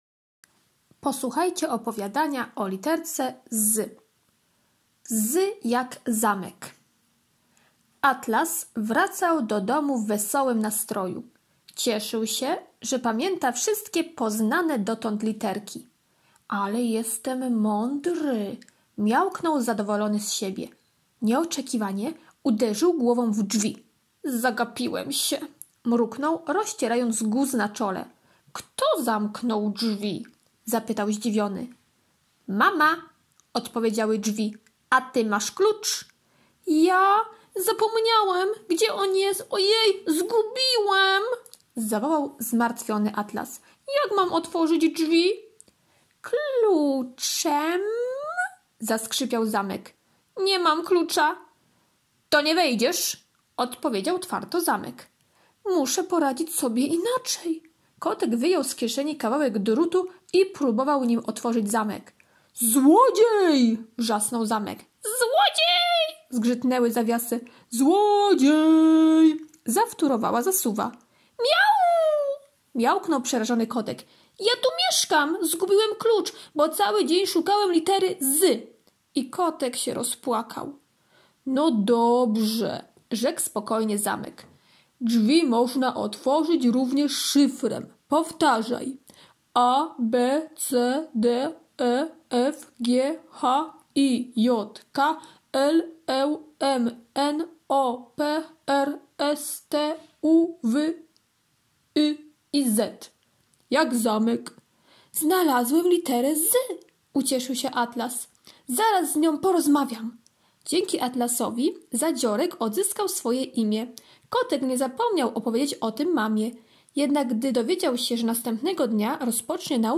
poniedziałek - opowiadanie "Z jak zamek" [6.63 MB] poniedziałek - karta pracy nr 1 [166.34 kB] poniedziałek -ćw. dla chętnych - kolorowanka "Z" [639.88 kB] wierszyk logopedyczny [190.87 kB] kolorowanka [113.11 kB]